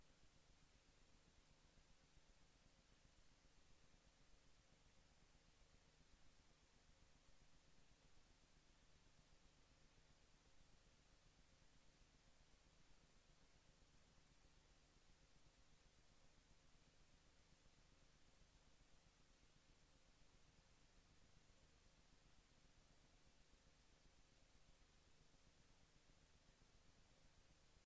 Gecombineerde commissievergadering 19 juni 2025